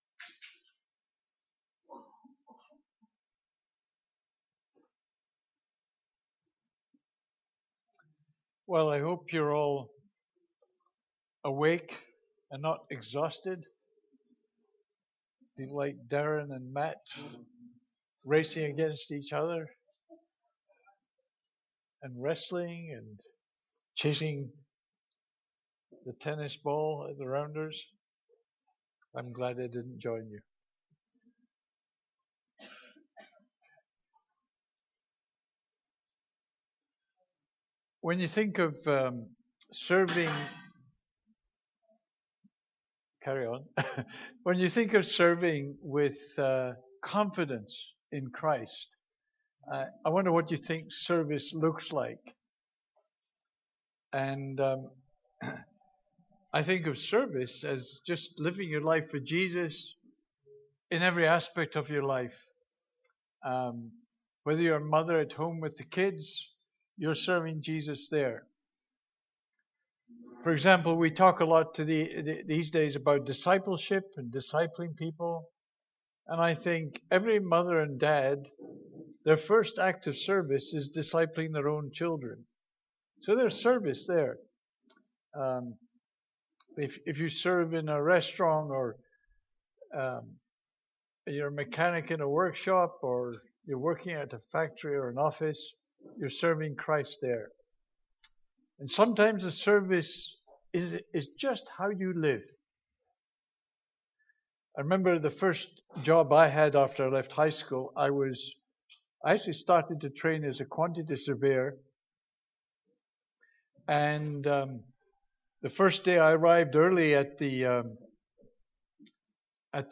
Media for Worship Service on Sat 26th Sep 2015 10:30
Sermon